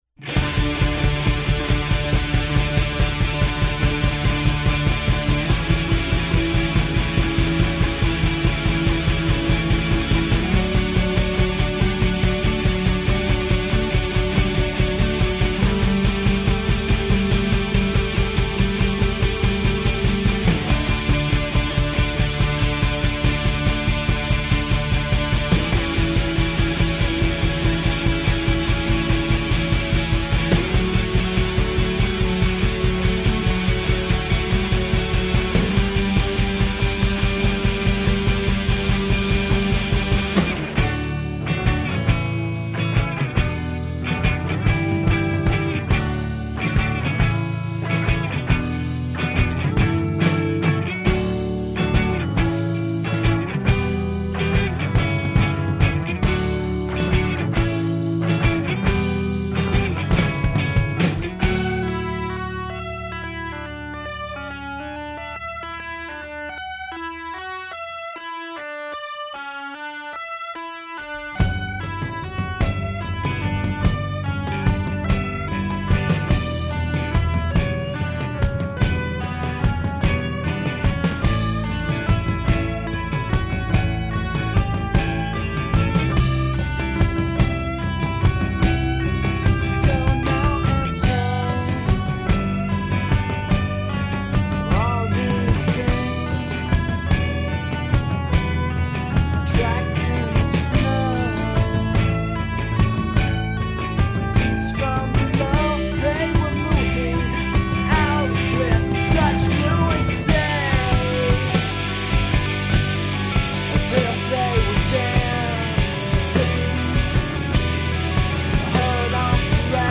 What comes out is probably best described as dark pop.